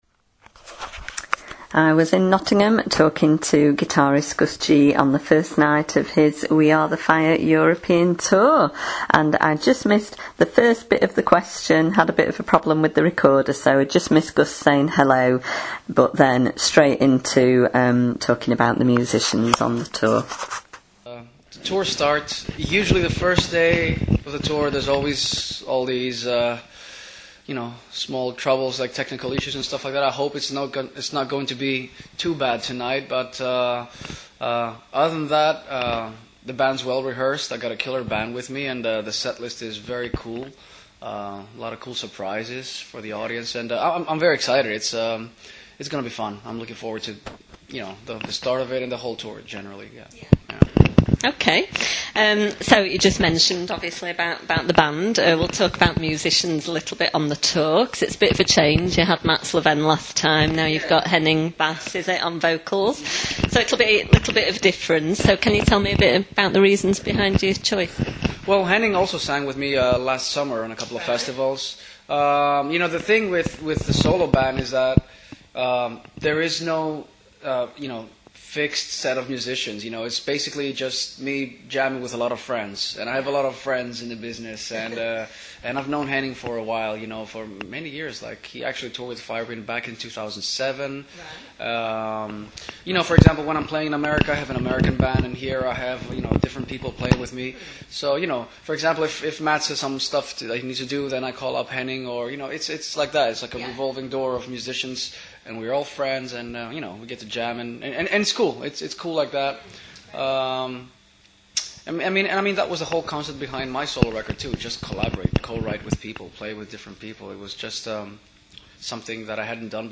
I caught up with Gus before his first show in Nottingham on Friday 20th February, where we discussed the tour and future plans including shows and the next solo album.
gus-g-int-2015.mp3